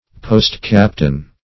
Post-captain \Post"-cap`tain\, n.